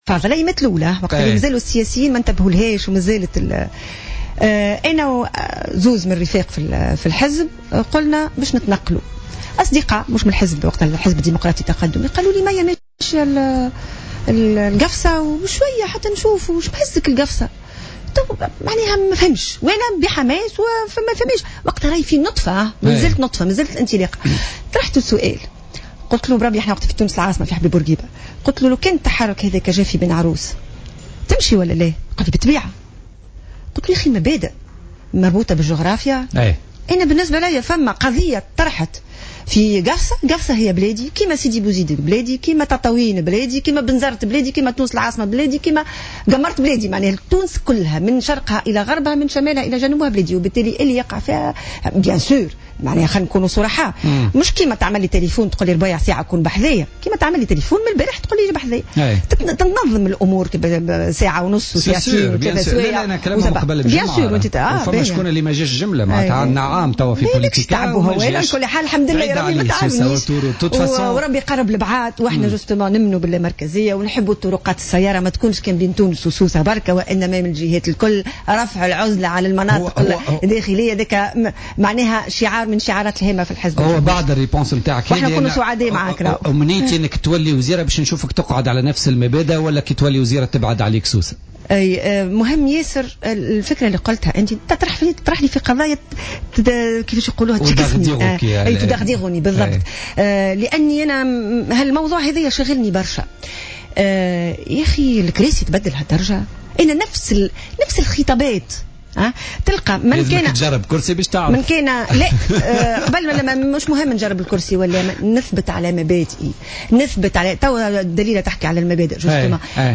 أكدت الأمينة العامة للحزب الجمهوري مية الجريبي ضيفة بوليتيكا اليوم الخميس 1 أكتوبر 2015 أن التفاهمات التي تجري حاليا بين حركة النهضة ونداء تونس حول تمرير قانون المصالحة بعيدة كل البعد عن المصلحة الوطنية وهي مسألة محاصصات حزبية ومحاولة من الحزبين للتموقع في الحكم وفق قولها.